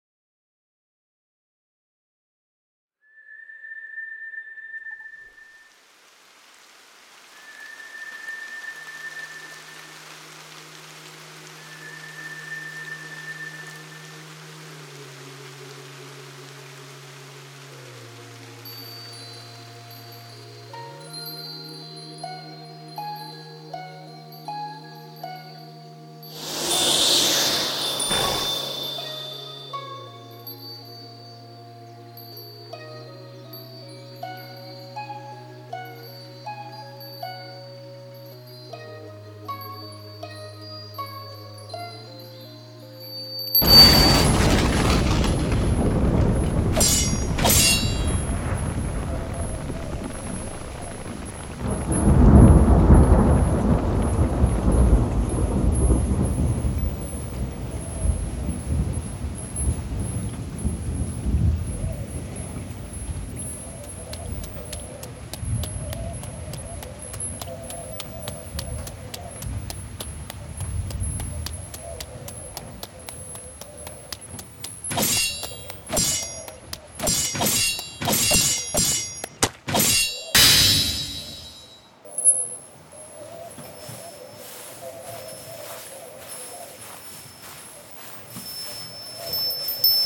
【声劇】雨垂れも遡